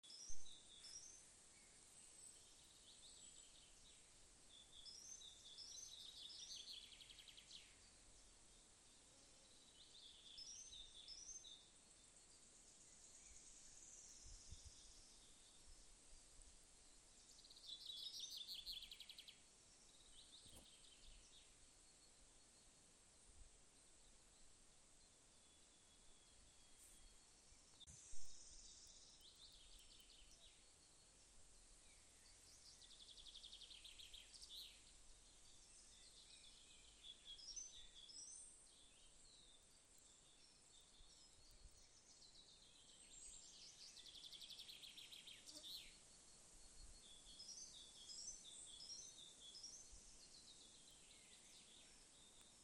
Bird Aves sp., Aves sp.
Administratīvā teritorijaViļakas novads
StatusSinging male in breeding season
NotesInteresē tas, kuram spalgi saucieni či či čir, či či čir.?